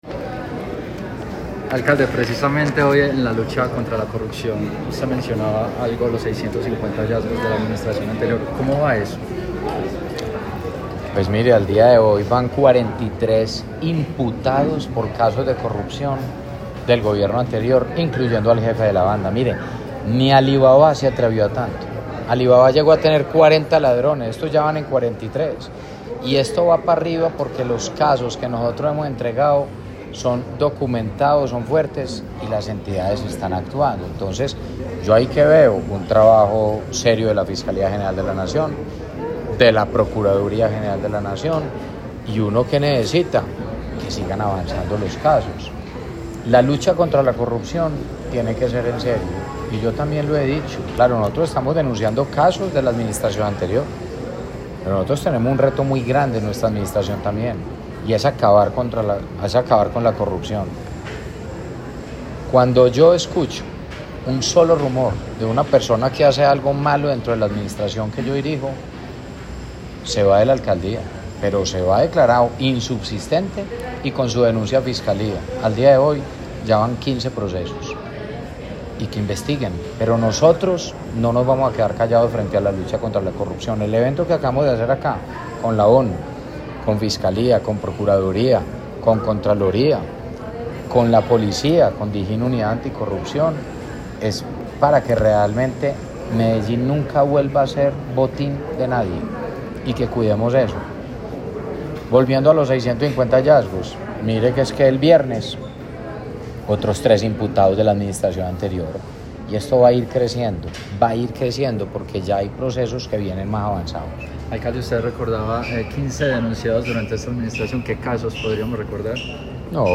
El alcalde participó en el evento conmemorativo del Día Nacional de Lucha contra la Corrupción, convocado por la Oficina de las Naciones Unidas contra la Droga y el Delito.
Declaraciones-alcalde-de-Medellin-Federico-Gutierrez-1.mp3